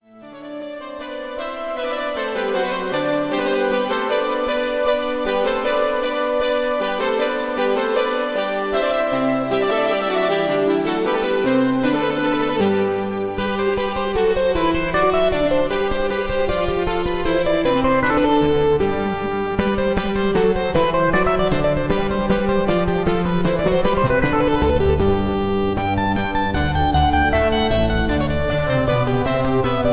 A MOST EXCELLENT CLASSICAL SYNTHESIZER CONCERT